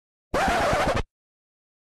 Error Scrash Efecto de Sonido Descargar
Error Scrash Botón de Sonido